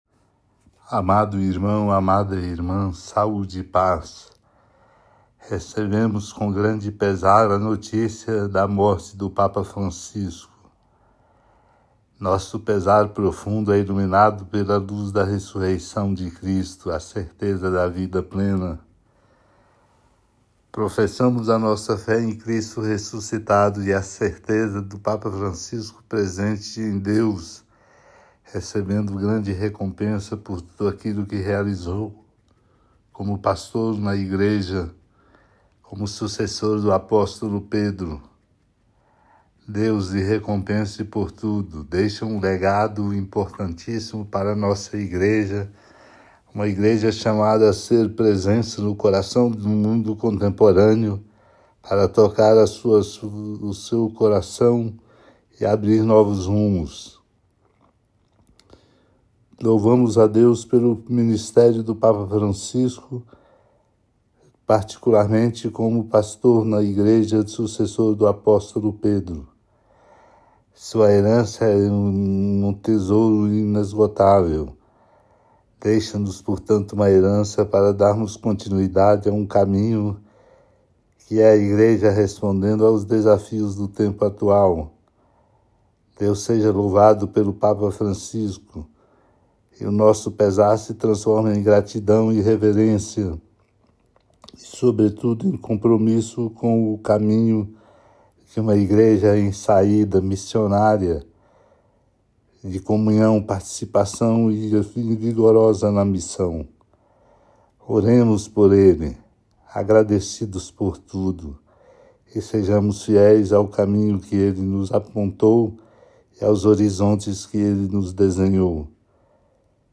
Mensagem de dom Walmor
Arcebispo metropolitano de Belo Horizonte